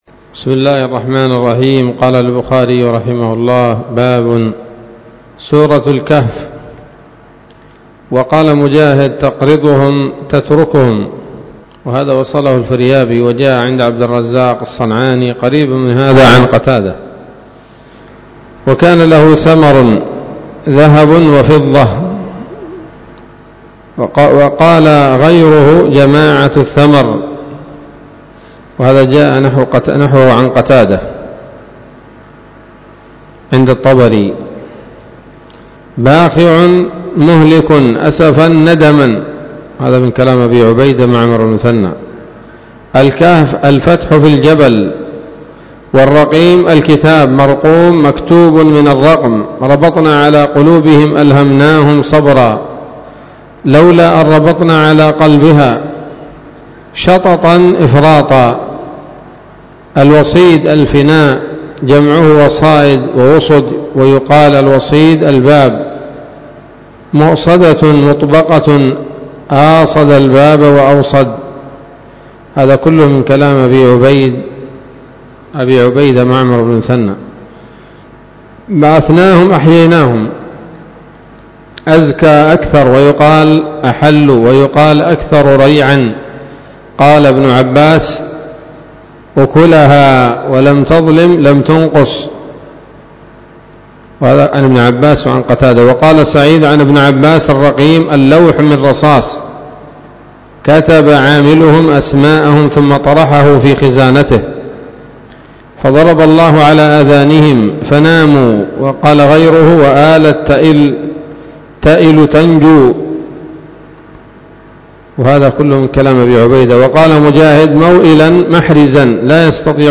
الدرس الثاني والستون بعد المائة من كتاب التفسير من صحيح الإمام البخاري